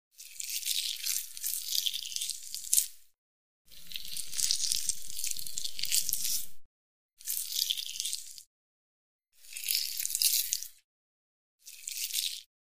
Звуки операции
Звук хирурга, ковыряющегося в теле человека